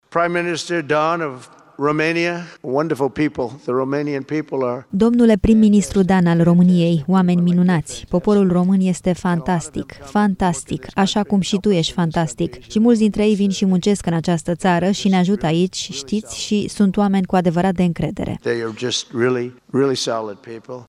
La prima reuniune a Consiliului pentru Pace, președintele Statelor Unite, Donald Trump, a avut cuvinte de laudă la adresa românilor.